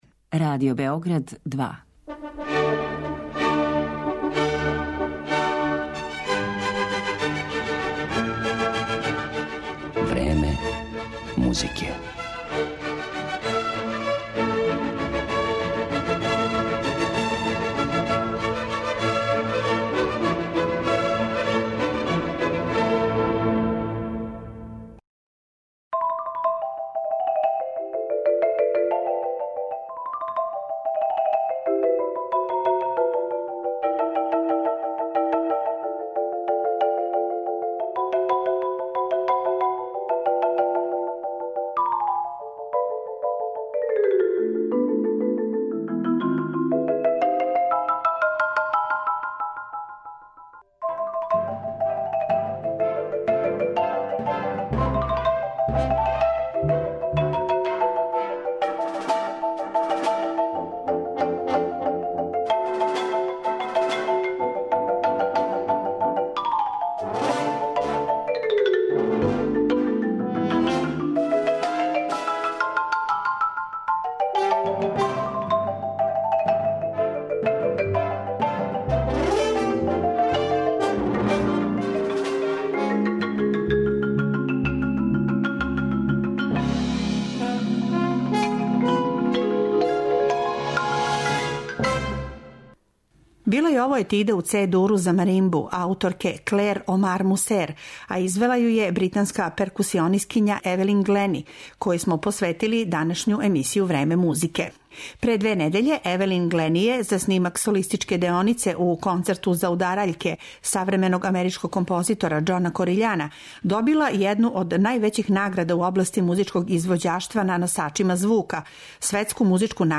Она је, недавно, добила још једну награду Grammy као најуспешнији инструментални солиста, па је то и био повод да чујете податке о њеном необичном животу и каријери, као и њене виртуозне снимке на којима свира како композиције које су баш њој посвећене, тако и разне аранжмане популарних класичних остварења, па и властита дела.